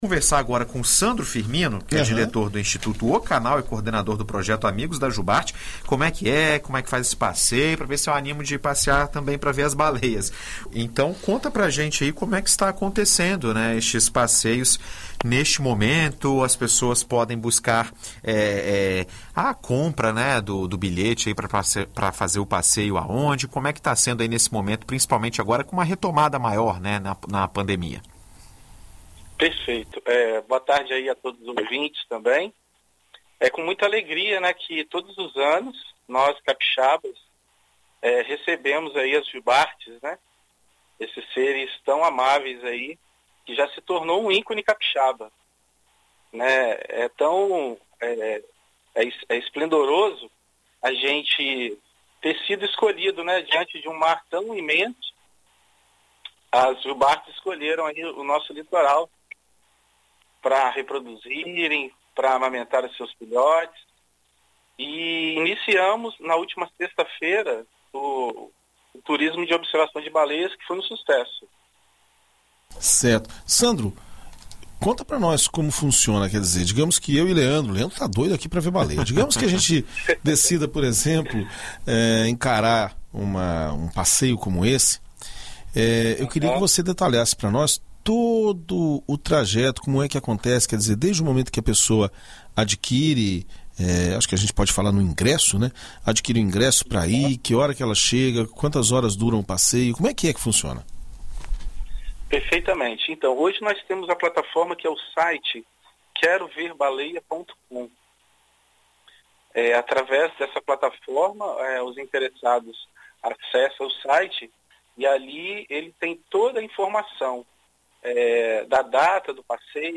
Na BandNews FM